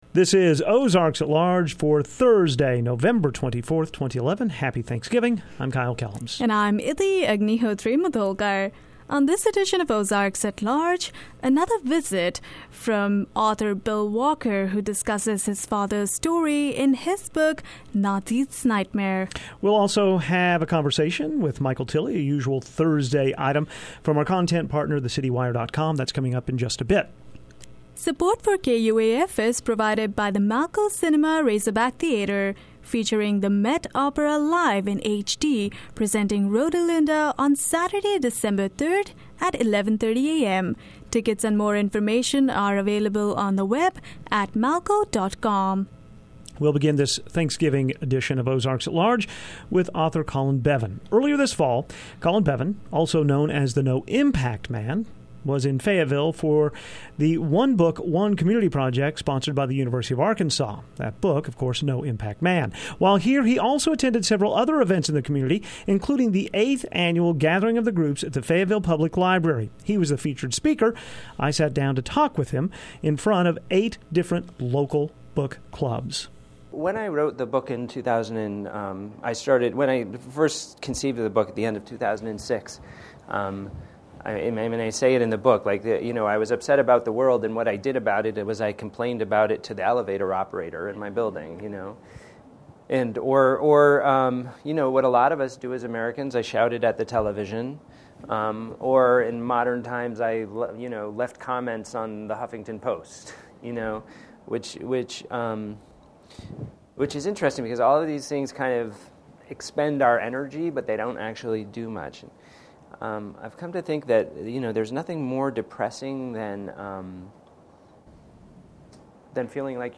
On this edition of Ozarks at Large, we have highlights from a moderated discussion at the Fayetteville Public Library that featured No Impact Man Colin Beavan.